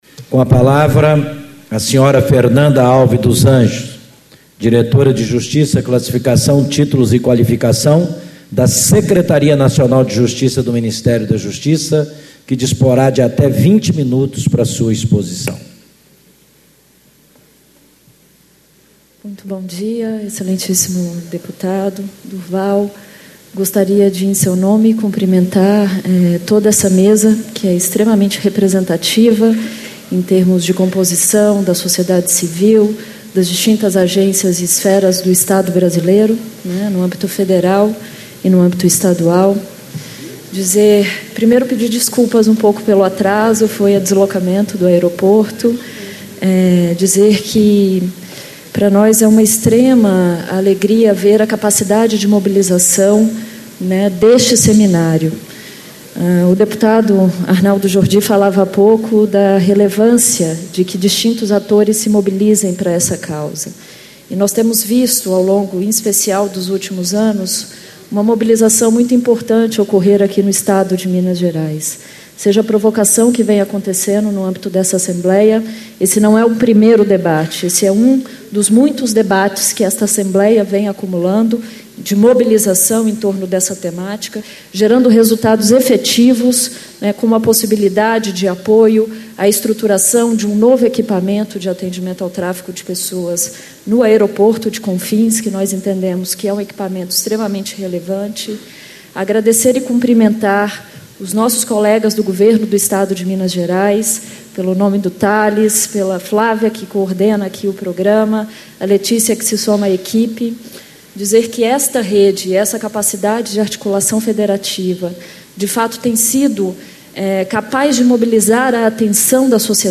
Ciclo de Debates Enfrentamento do Tráfico de Pessoas em Minas Gerais
Discursos e Palestras